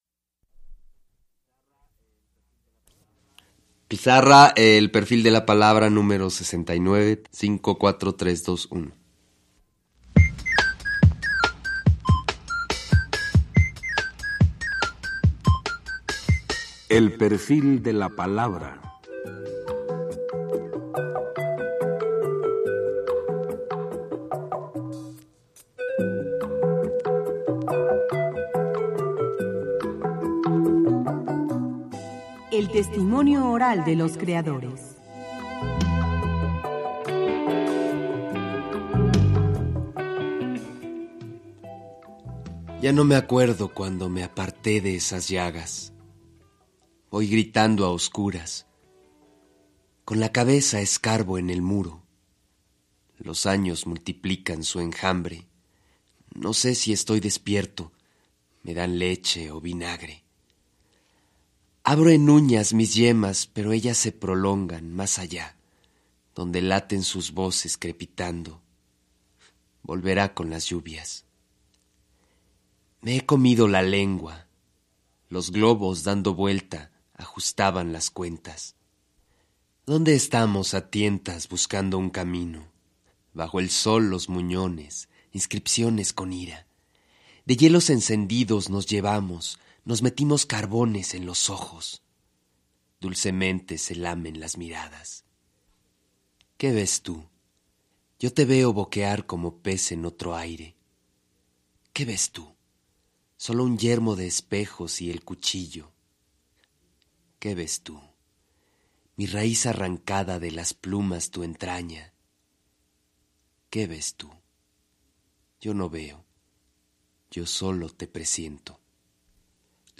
Escucha a Ludwing Zeller entrevistado en el programa “El perfil de la palabra”, transmitido en 1987, en el que habla sobre la literatura y su obra.